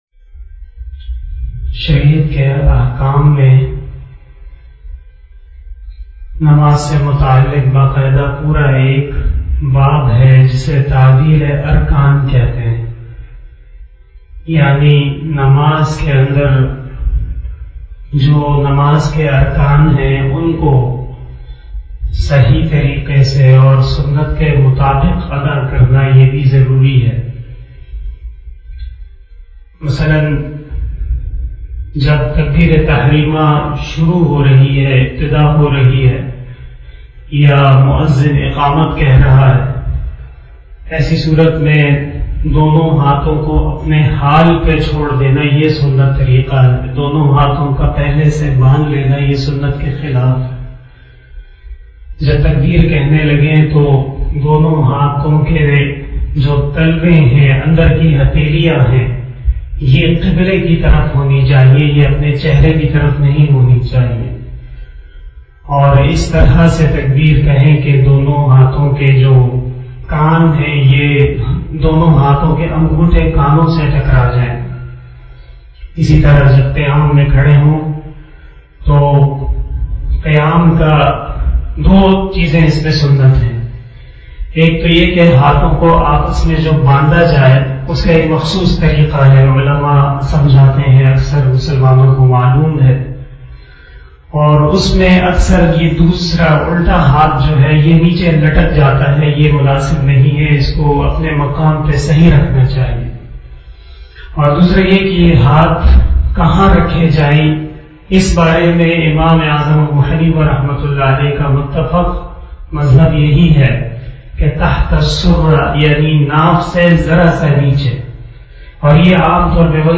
034 After Asar Namaz Bayan 17 July 2021 (06 Zilhajjah 1442HJ) Saturday